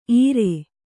♪ īre